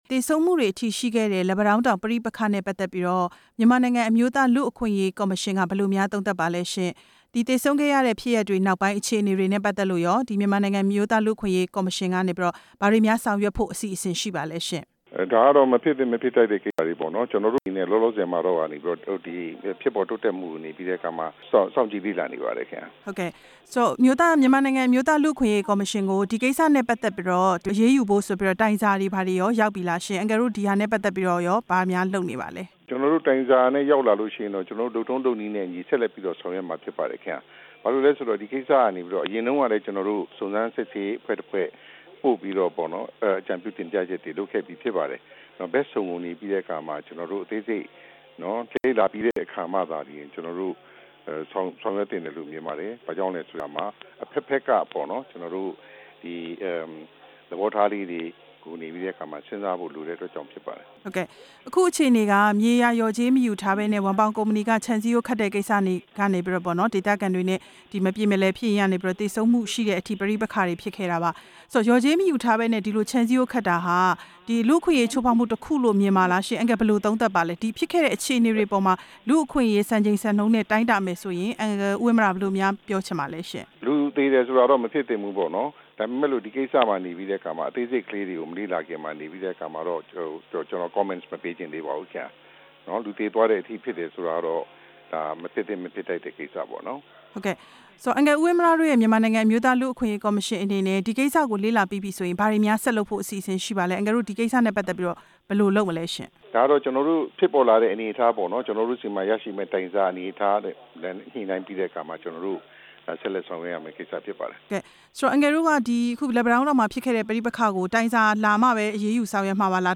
လက်ပံတောင်းပဋိပက္ခ အမျိုးသားလူ့အခွင့်အရေးကော်မရှင်နဲ့ မေးမြန်းချက်